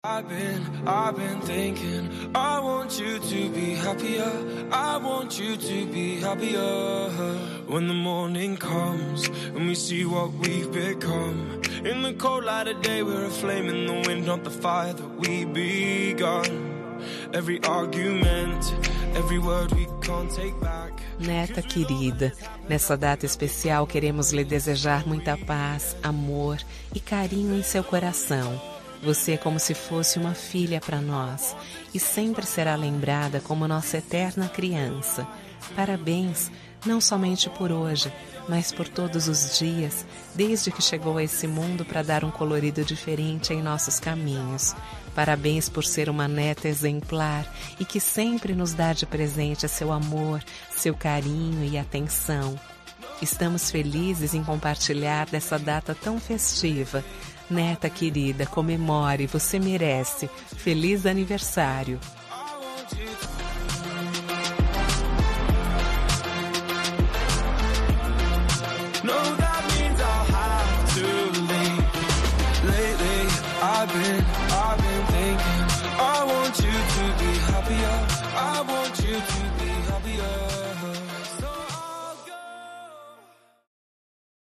Aniversário de Neta – Voz Feminina – Cód: 131023